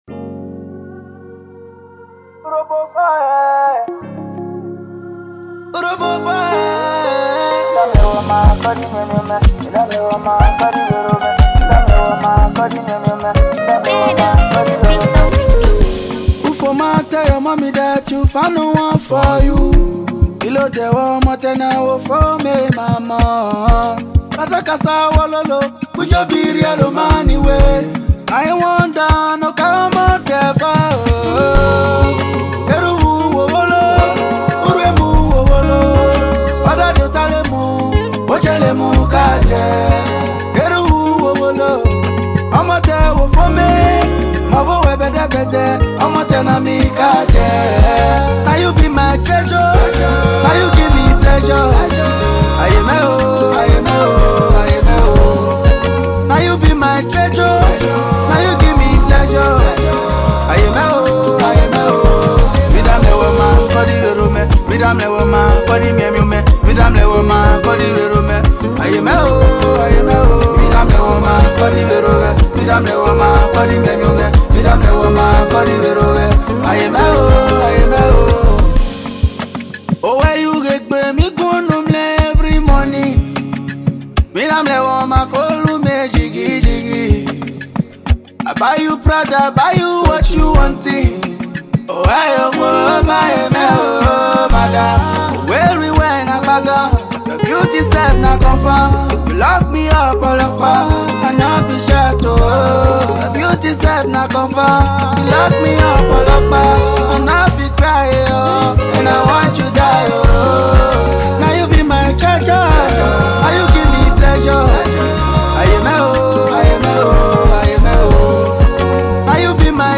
Nigerian Afro Pop/ AfroBeat artiste